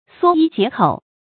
縮衣節口 注音： ㄙㄨㄛ ㄧ ㄐㄧㄝ ˊ ㄎㄡˇ 讀音讀法： 意思解釋： 指省吃省穿，生活節儉。